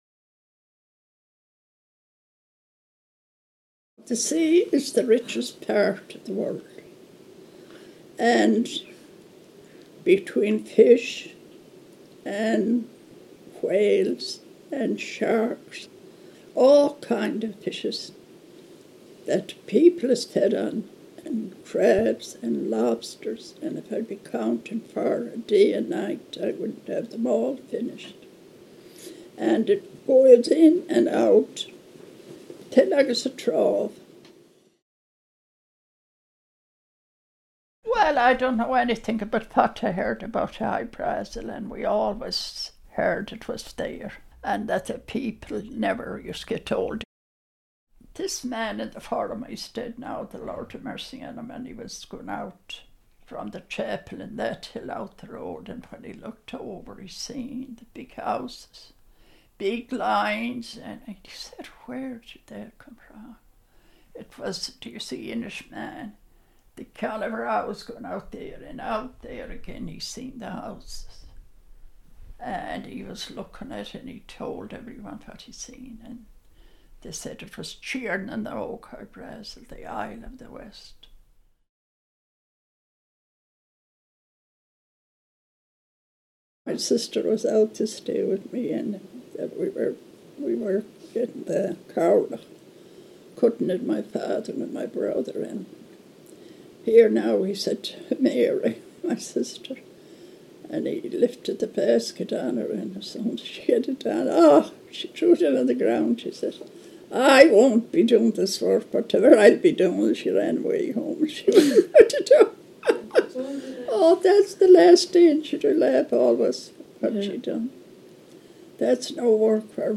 The project began at sea.
She spoke of the sea’s demands and gifts — fish and turf, driftwood and footballs — ordinary materials of survival that became metaphors for exchange and resilience. Her words folded into the sound of the waves, creating a space of listening and reflection, where myth emerged not as story, but as lived rhythm.